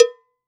cowbell_01.wav